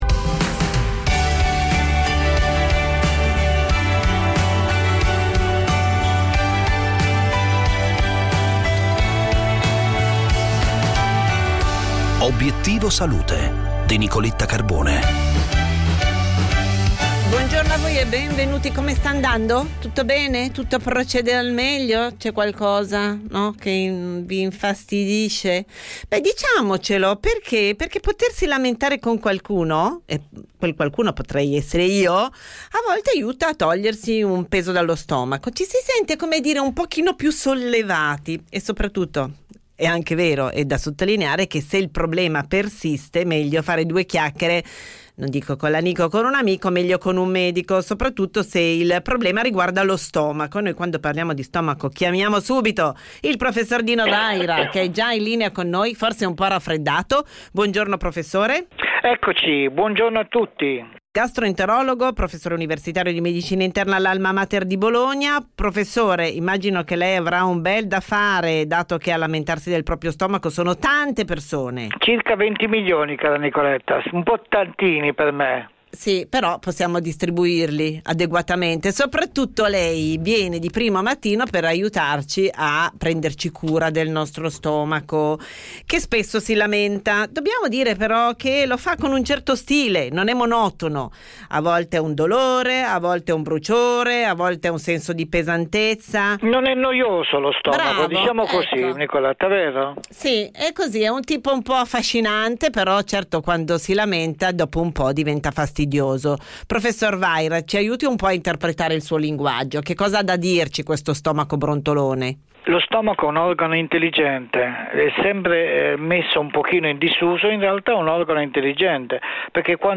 Il tempo di un caffè e una chiacchiera che aiuta a stare bene. Con gli esperti strategie, metodi e consigli pratici per iniziare bene la giornata.